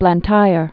(blăn-tīr)